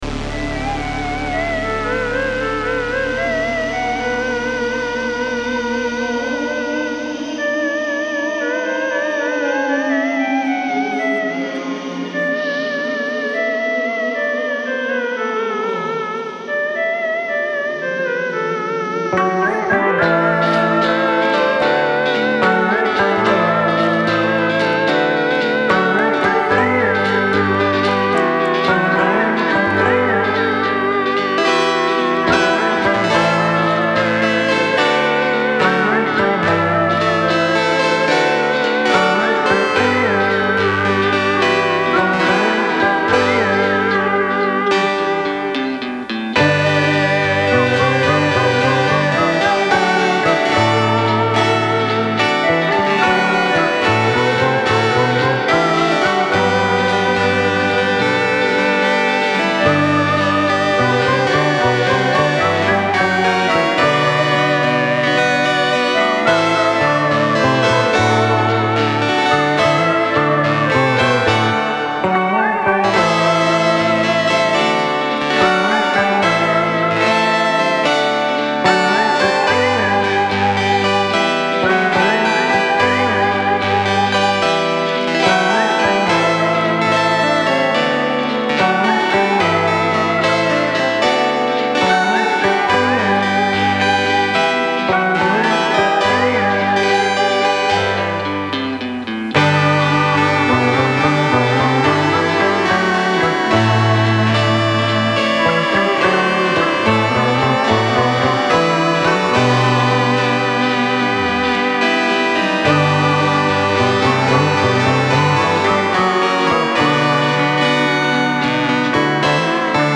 Genre : Experimental